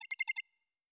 Holographic UI Sounds 101.wav